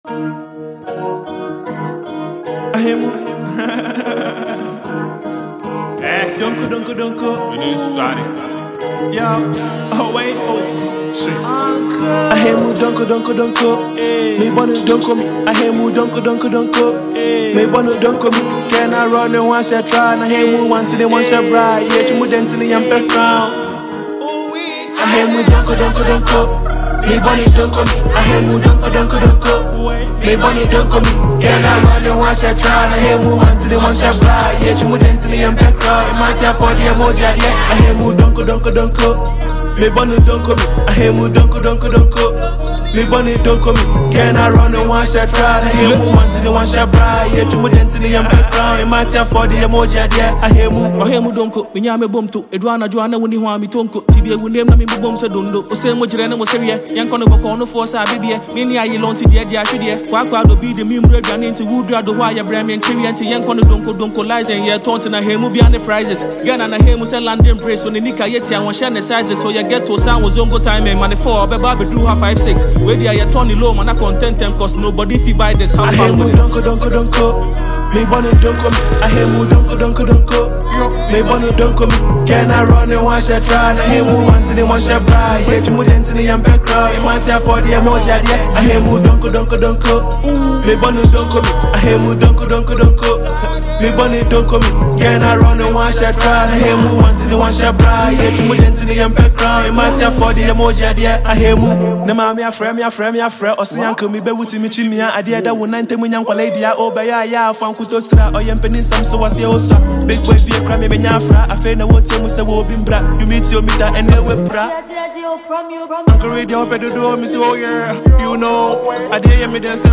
two amazing Ghanaian rappers.